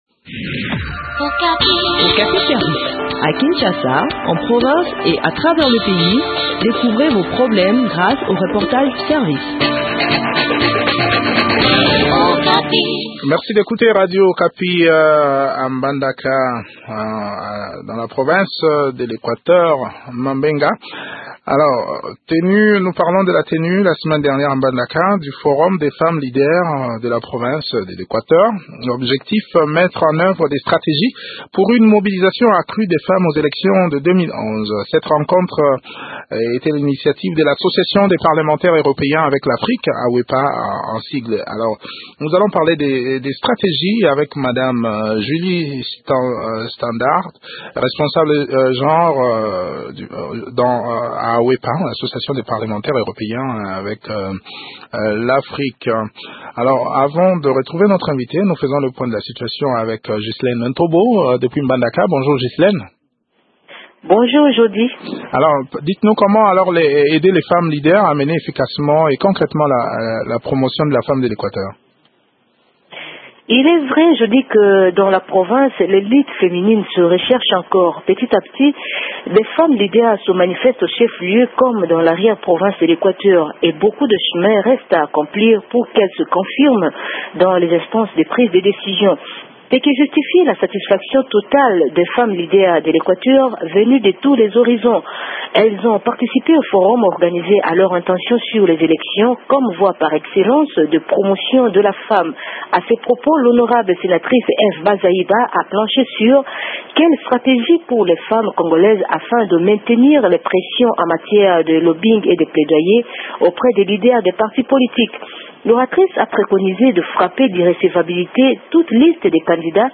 Le point sur le déroulement de ce séminaire atelier dans cet entretien